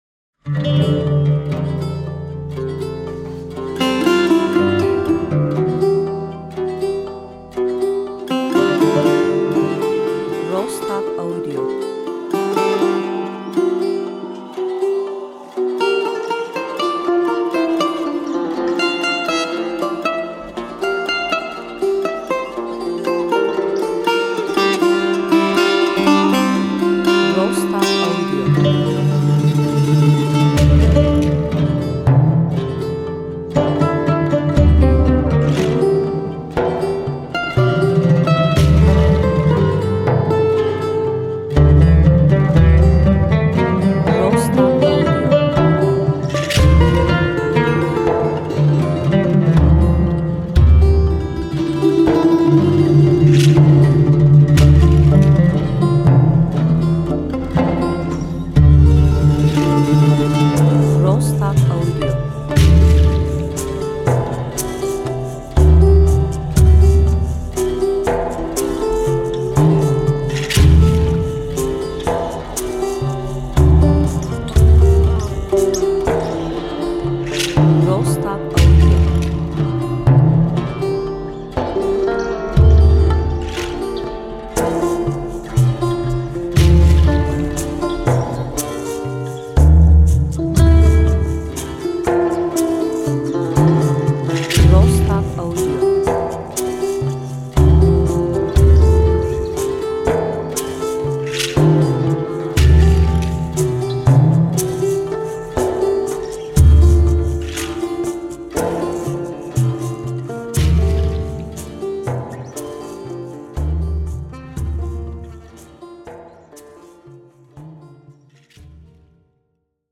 enstrümantal music müzik duygusal epik epic